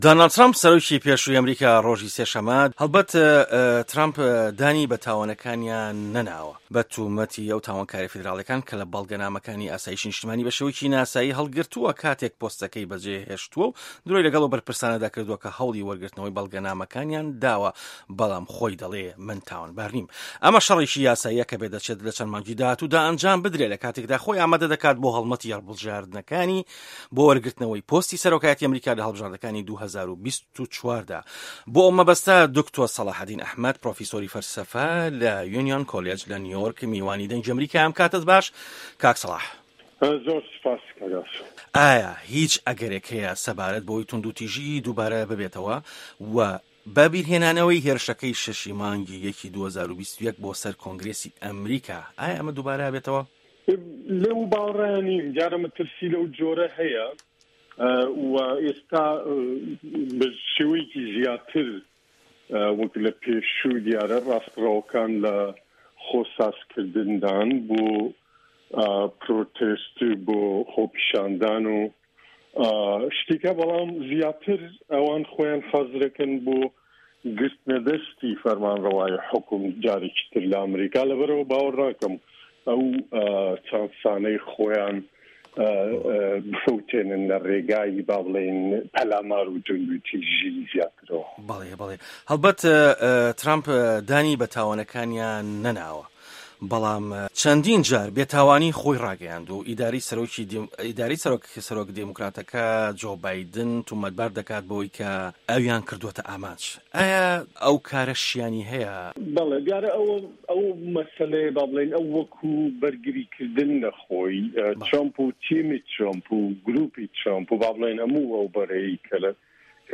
ئه‌مه‌ریکا - گفتوگۆکان